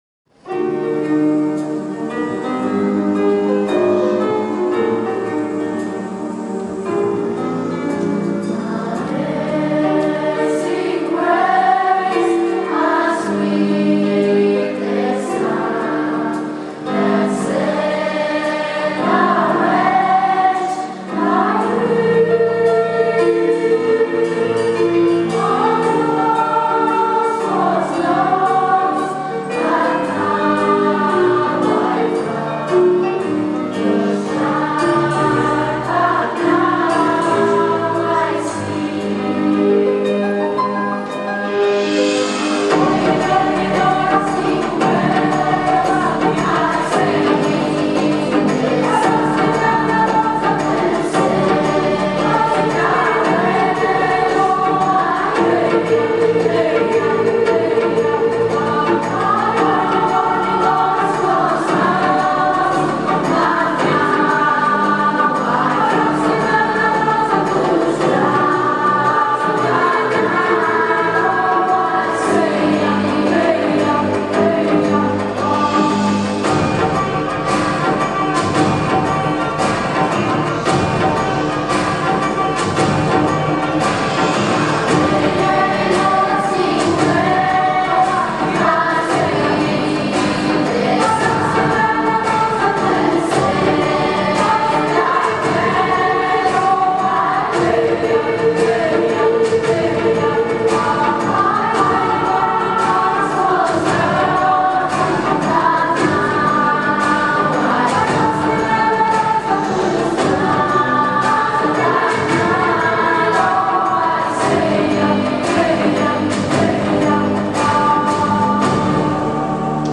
arrangiamento